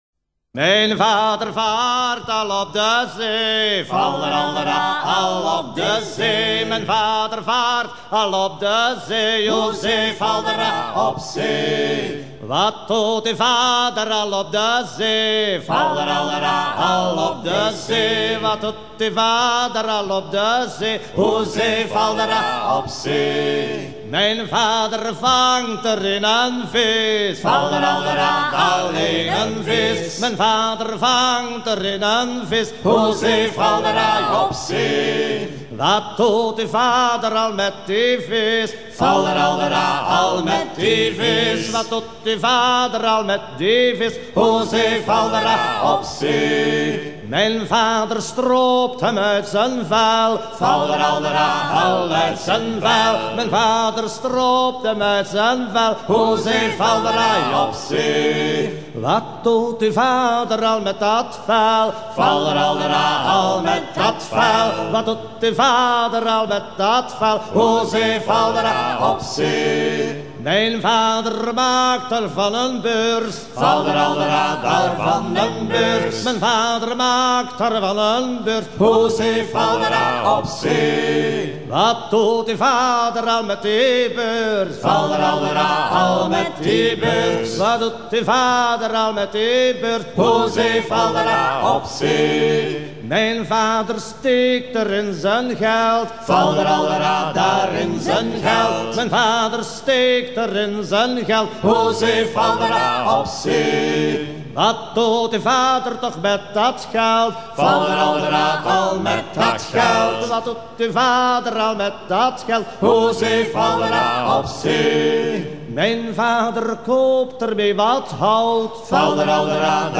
gestuel : à pomper
circonstance : maritimes
Pièce musicale éditée